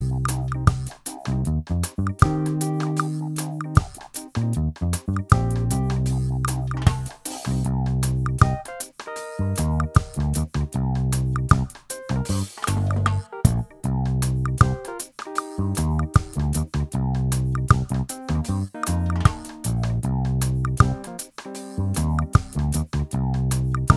Minus Guitars Reggae 3:14 Buy £1.50